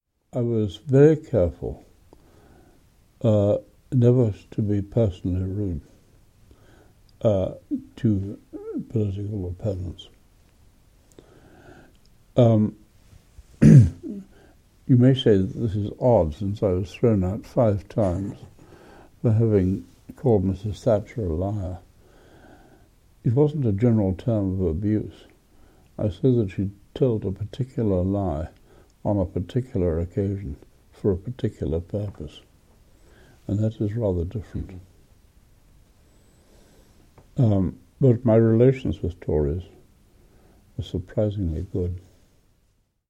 Even in the setting of an oral history interview he proved formidable: seizing our interviewer’s notes before starting, he often seemed to be conducting the interview himself!